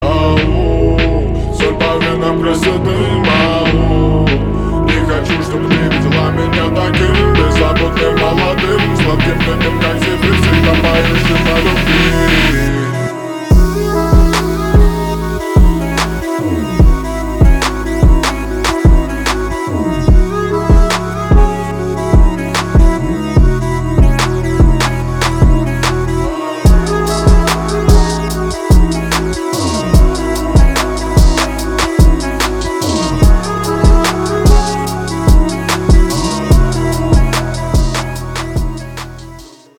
Ремикс
грустные